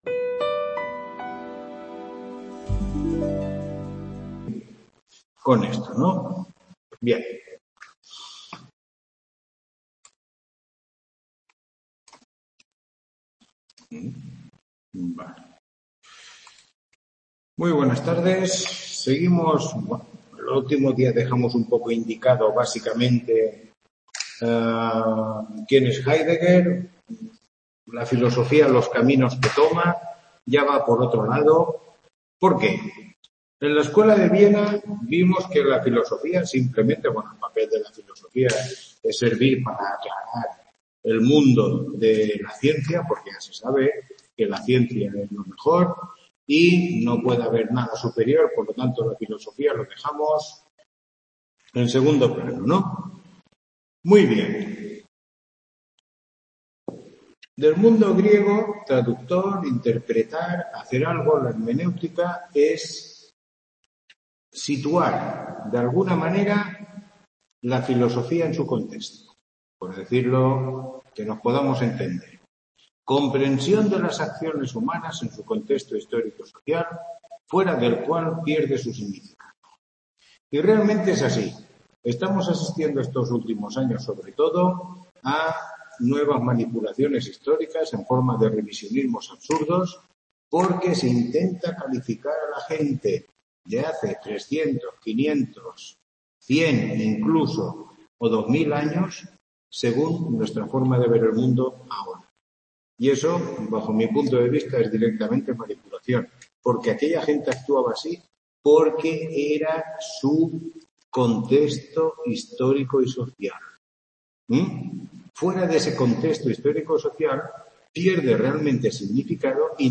Tutoría 9